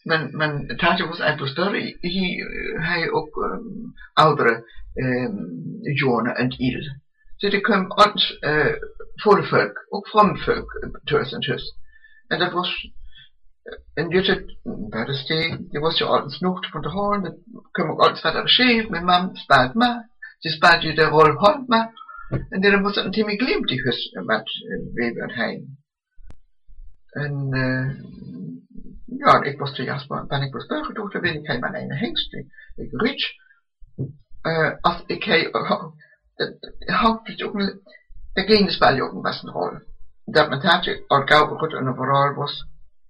16 December 2012 at 2:09 pm Sounds like some variety of Dutch, though the audio is too distorted to make much out with certainty.
16 December 2012 at 4:08 pm I hear the similarity with Dutch – especially in the [ɛɪ] diphthong.